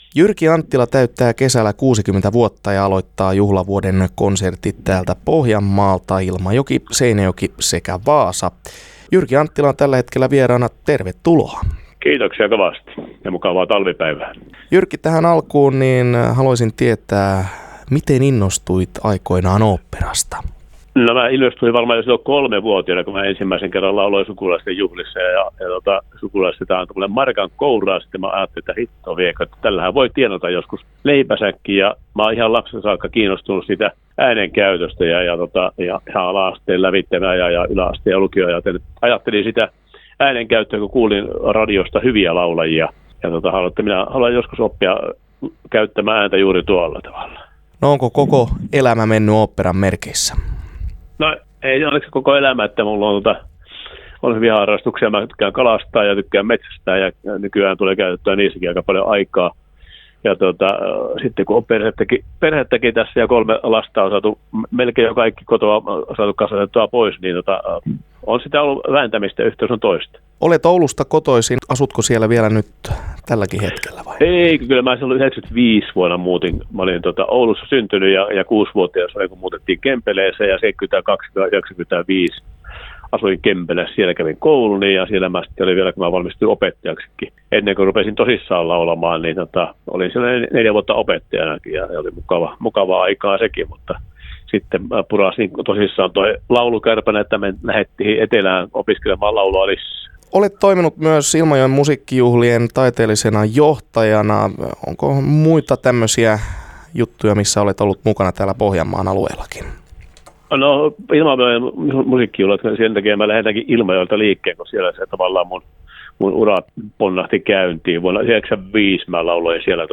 haastattelee